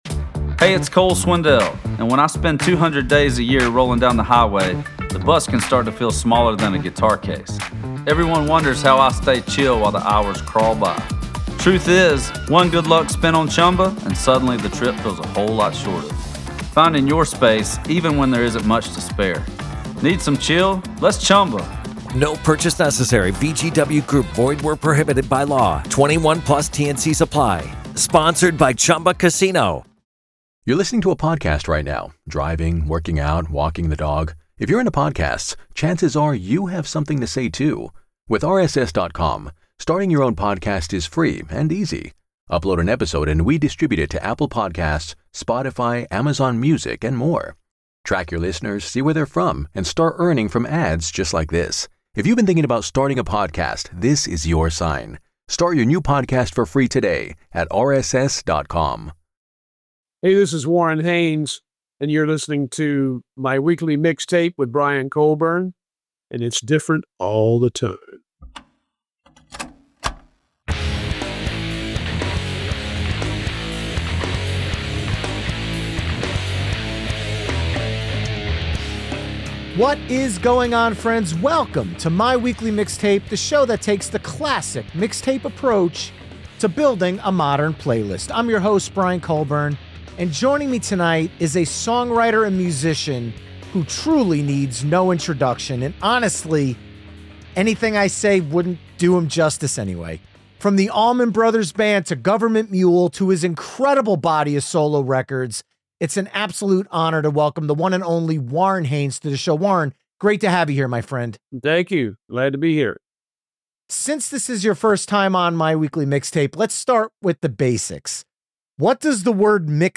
This week, I’m sitting down with the legendary Warren Haynes to revisit Tales of Ordinary Madness — his debut solo album, now newly remixed and remastered more than thirty years after its original release.